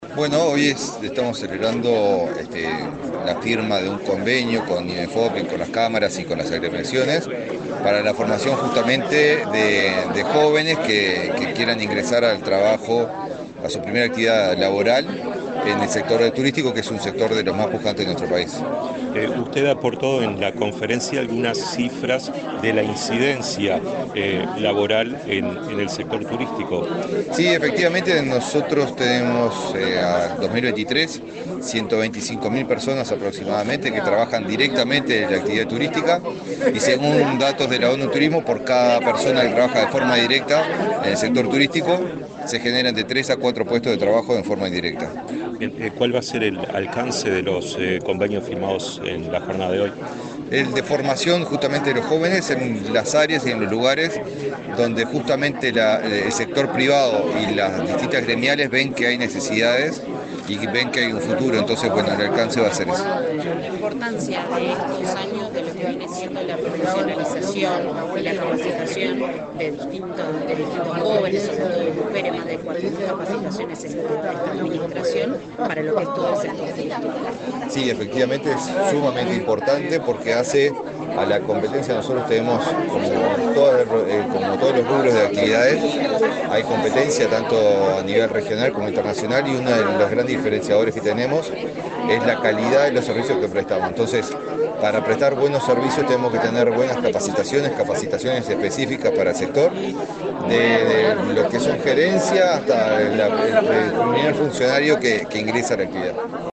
Declaraciones del ministro de Turismo, Eduardo Sanguinetti
Declaraciones del ministro de Turismo, Eduardo Sanguinetti 17/07/2024 Compartir Facebook X Copiar enlace WhatsApp LinkedIn El ministro de Turismo, Eduardo Sanguinetti, dialogó con la prensa, este miércoles 17 en Montevideo, luego de participar en el acto de lanzamiento de cursos de formación vinculados al sector turístico.